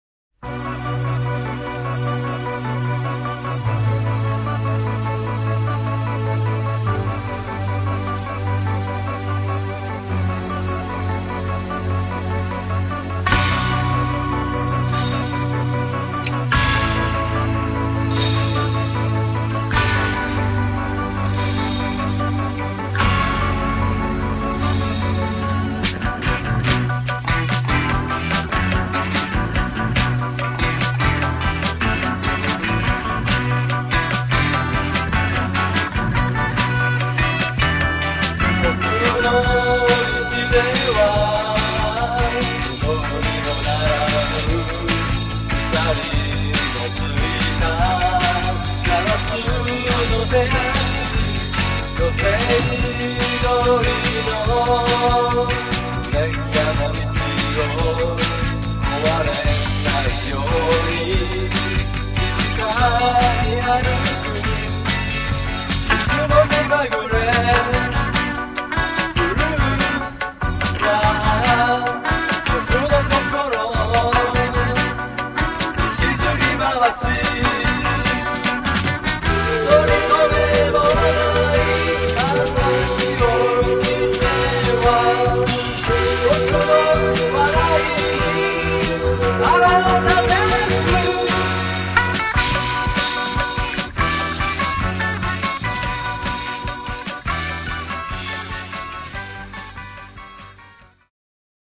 歌の曲がほとんどで、詩はバンドの他のメンバーのものもあり、他にもいろいろなところから取られている。